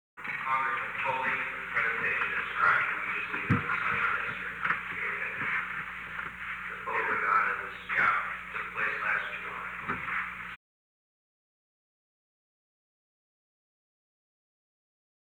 Secret White House Tapes
Conversation No. 911-19
Location: Oval Office
The President met with an unknown man.